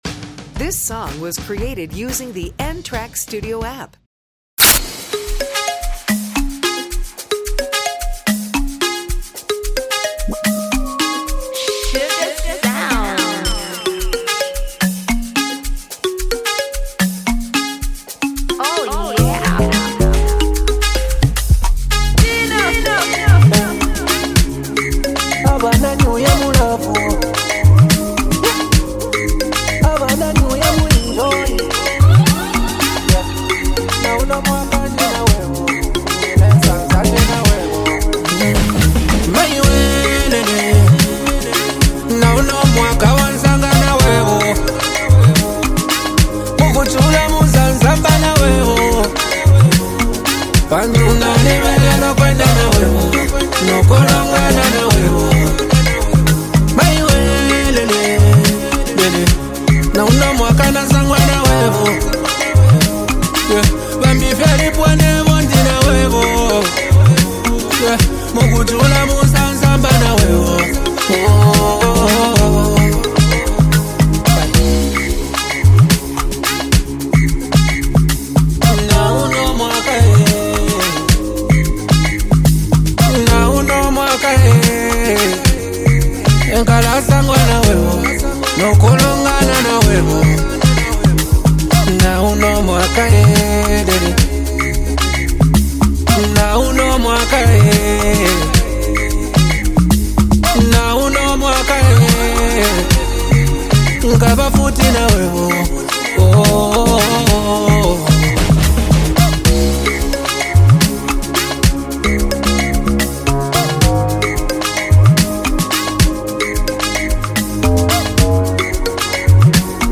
love song
melodic delivery adds warmth and sincerity